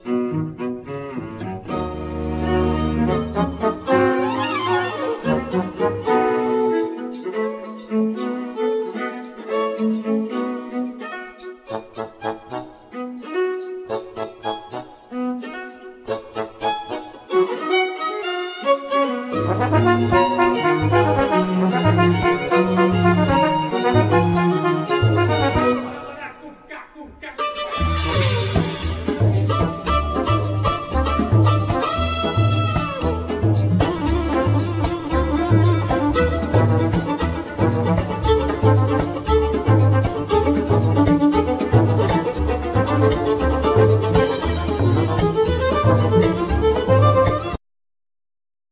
Percussions
Tuba
Violin
Viola
Cello